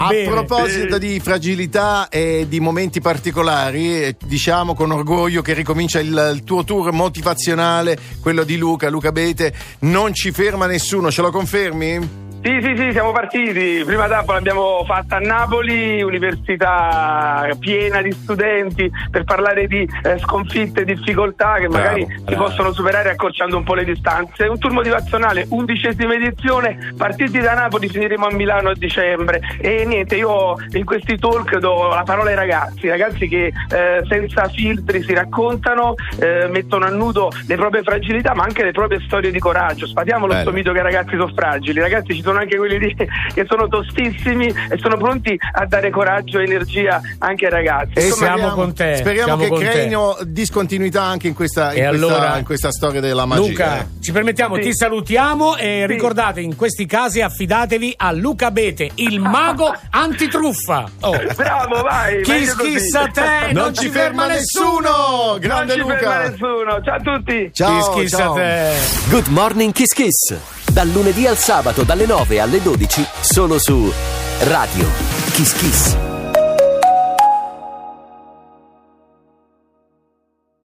Nella puntata di Good Morning Kiss Kiss del 15 marzo 2025, Luca Abete è stato ospite